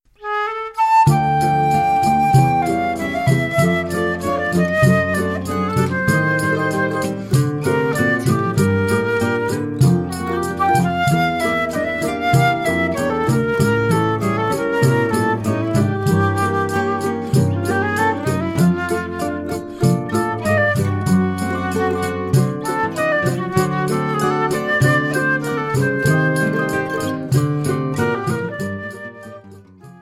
flute
cavaquinho
Choro ensemble